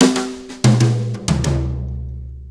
Brushes Fill 69-08.wav